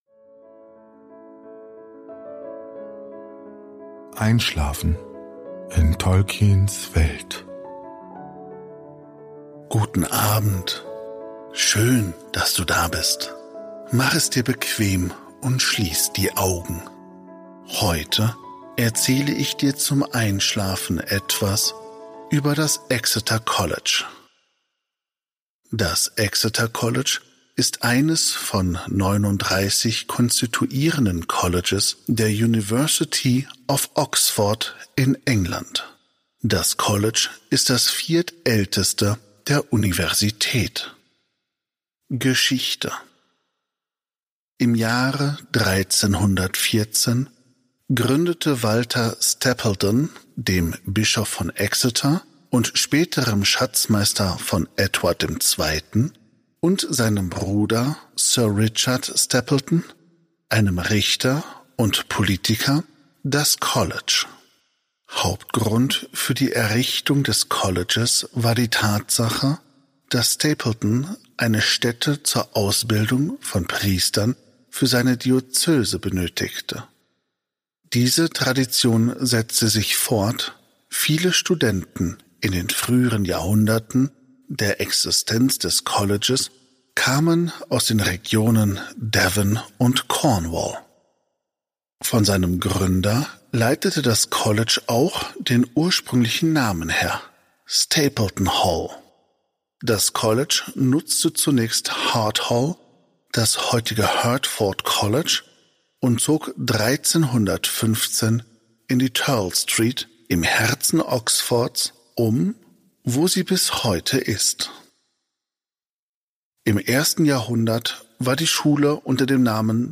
Gutenachtgeschichten aus der Ardapedia